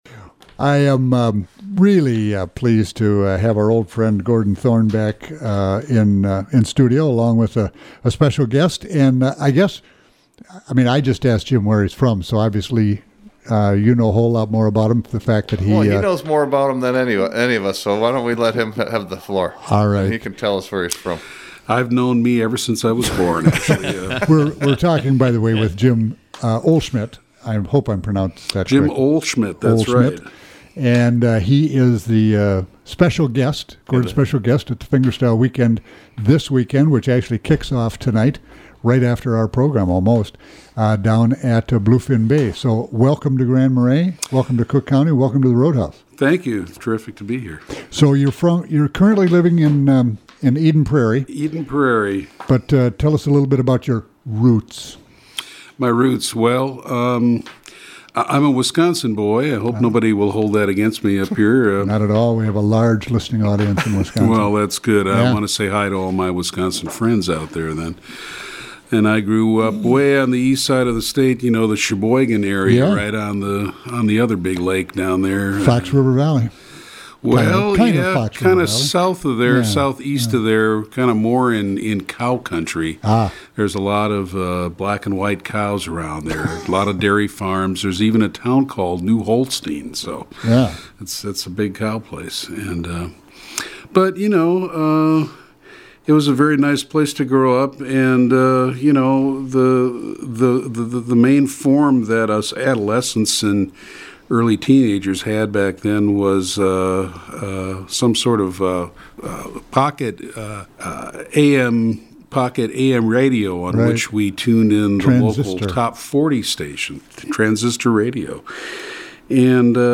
Amazing guitar work--take a listen.